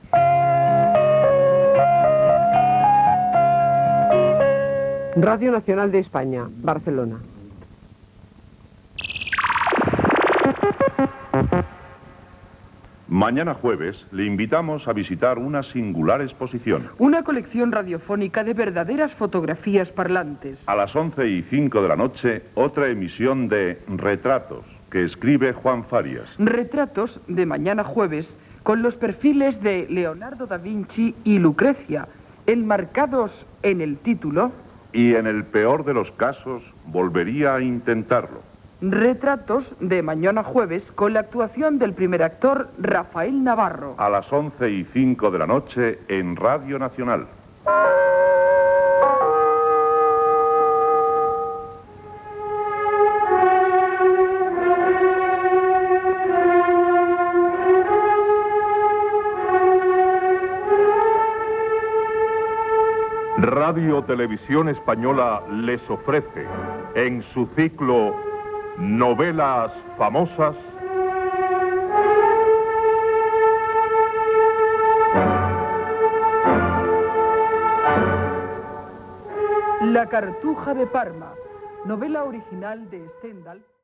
Sintonia de l'emissora, identificació, promoció de "Retratos" i inici del programa.
Ficció